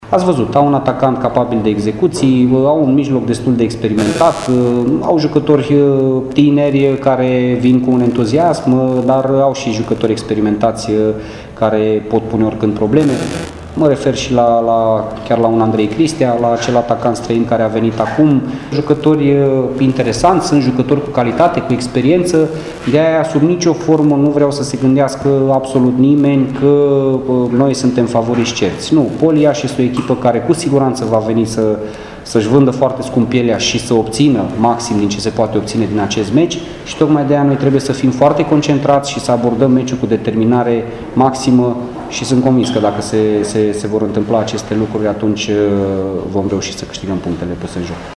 Faptul că Iașul a încasat opt goluri în ultimele două meciuri nu îi face pe arădeni favoriți în confruntarea din această seară, e de părere antrenorul Laszlo Balint: